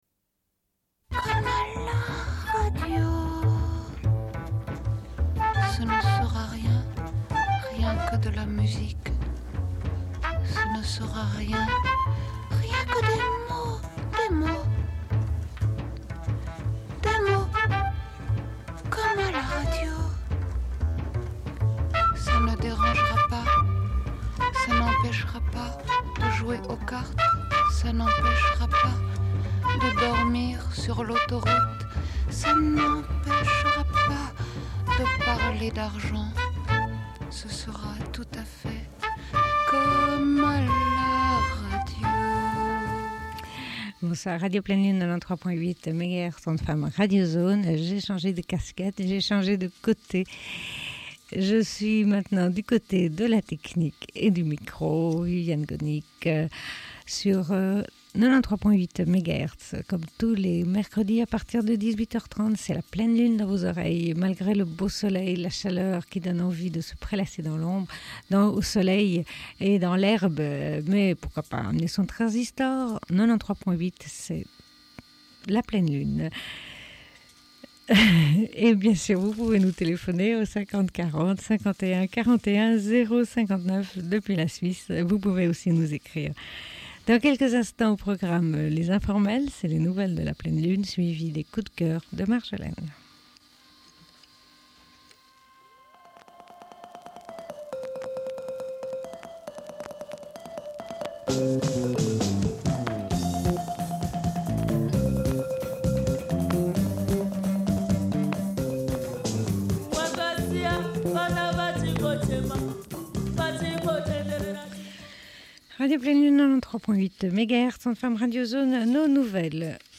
Bulletin d'information de Radio Pleine Lune du 19.06.1996 - Archives contestataires
Une cassette audio, face B